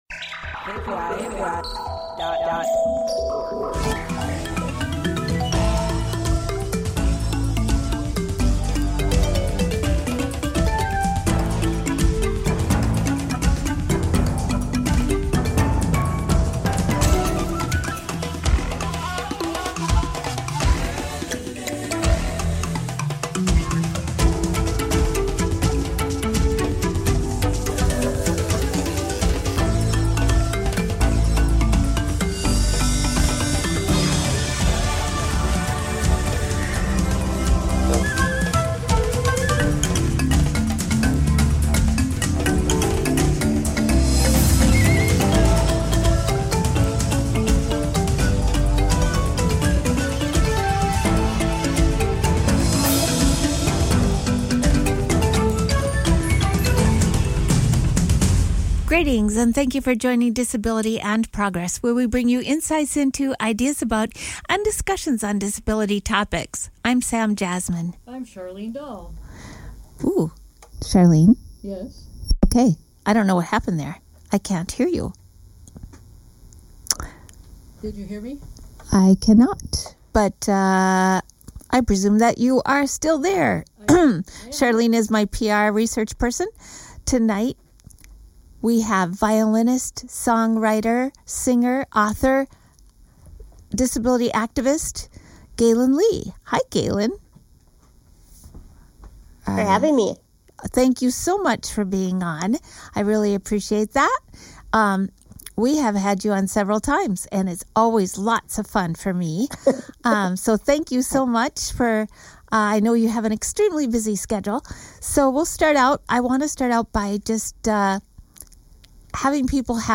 Gaelynn is an author, songwriter, musician, and Disability Rights activist.